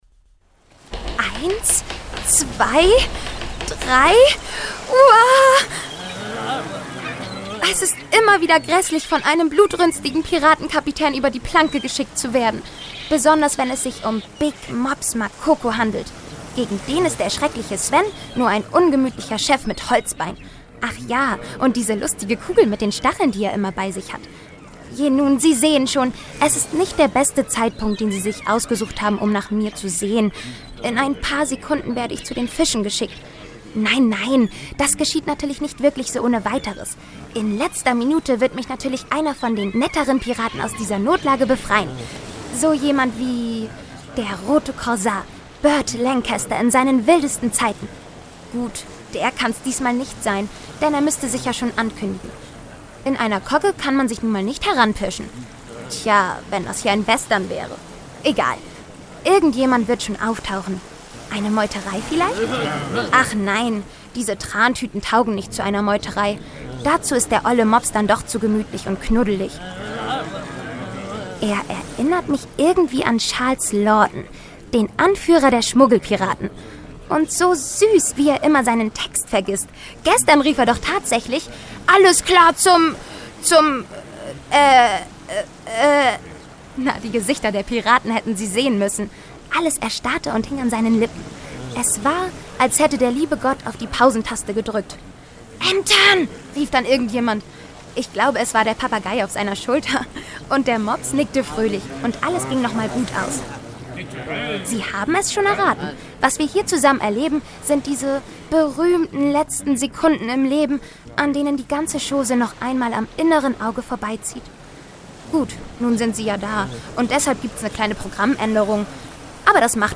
Sprecherin, Synchronsprecherin, Stationvoice
Werbung - Trivago Spotify-SummerBlast-Experts